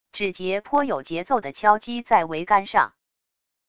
指节颇有节奏的敲击在桅杆上wav音频